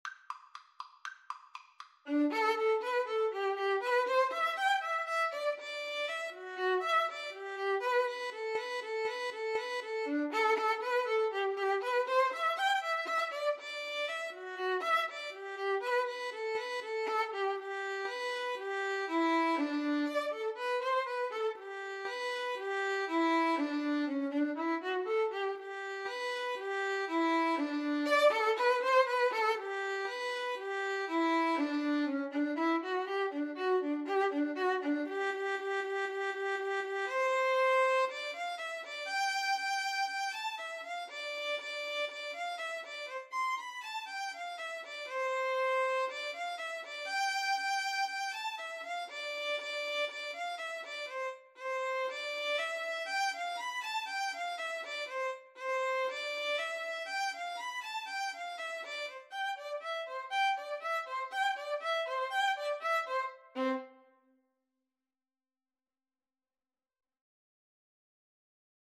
Allegro = 120 (View more music marked Allegro)
Violin Duet  (View more Easy Violin Duet Music)
Classical (View more Classical Violin Duet Music)